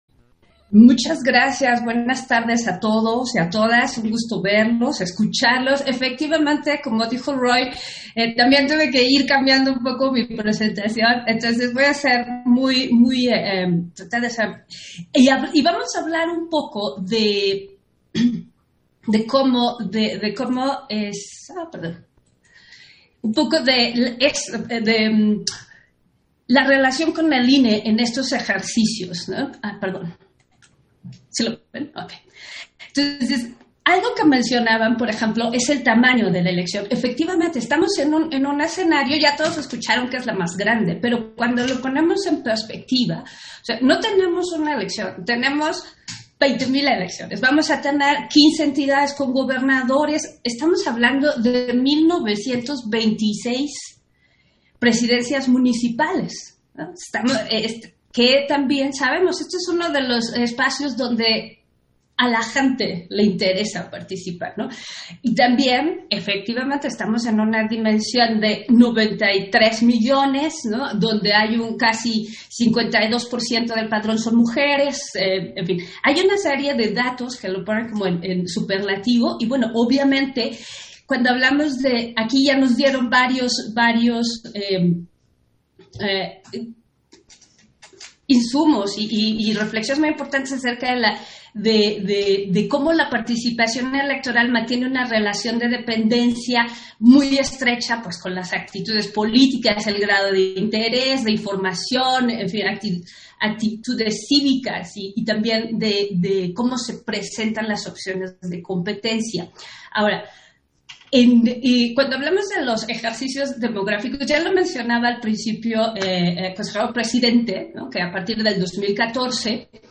190521_AUDIO_INTERVENCIÓN-CONSEJERA-NORMA-DE-LA-CRUZ-ENCUESTAS-Y-ELECCIONES-RUMBO-AL-6-DE-JUNIO - Central Electoral